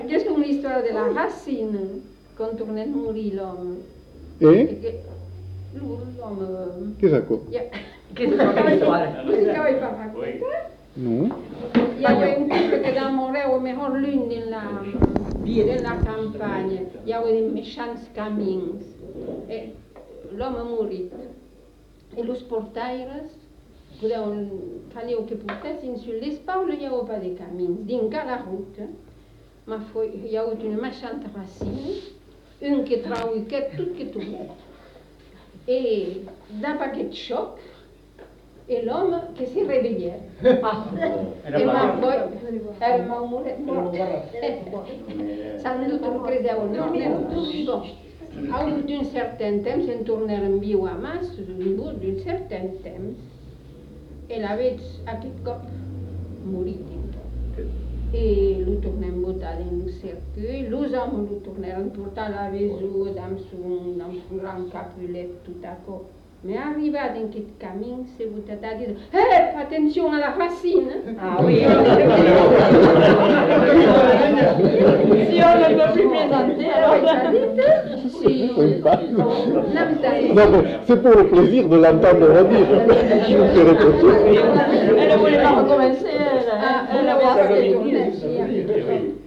Lieu : Bazas
Genre : conte-légende-récit
Effectif : 1
Type de voix : voix de femme
Production du son : parlé
Classification : conte facétieux